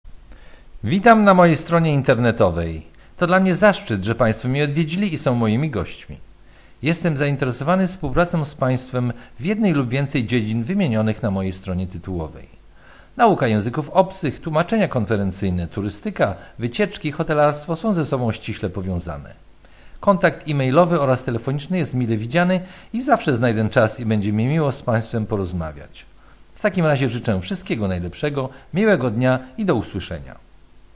Powitanie